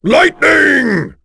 Kaulah-Vox_Skill2-3.wav